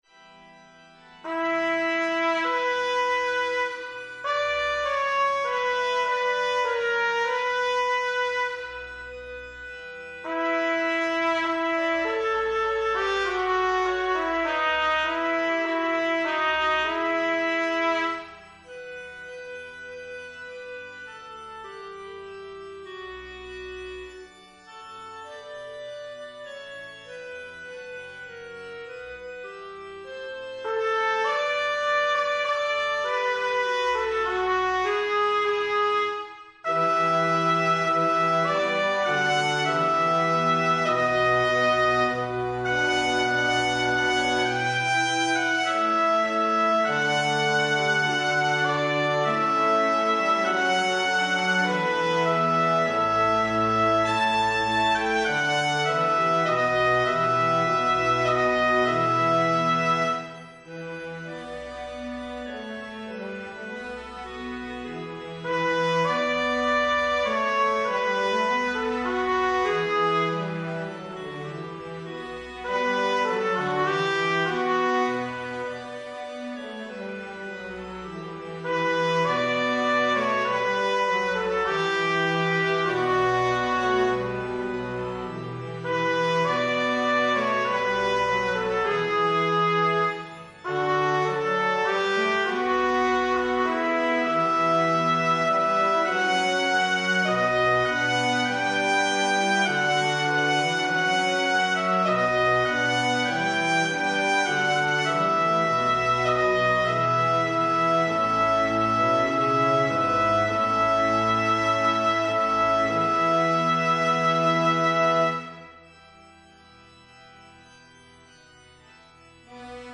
Soprano
Evensong Setting